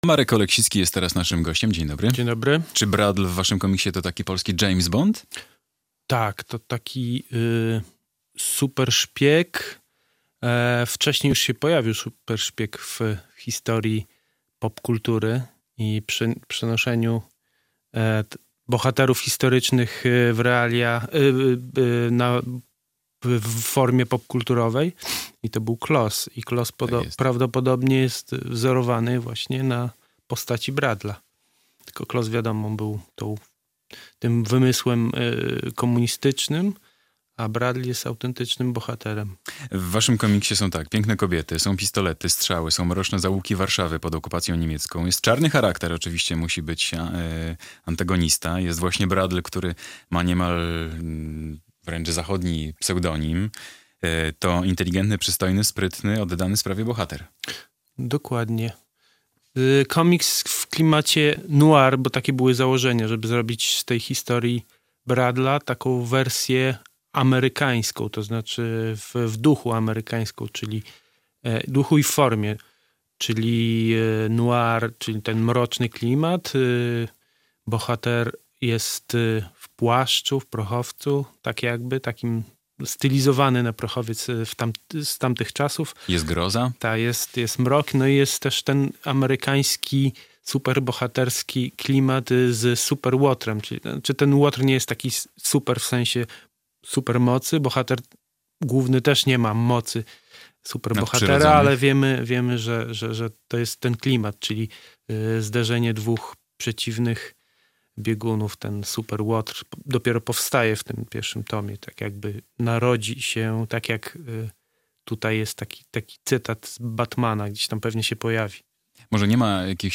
rysownik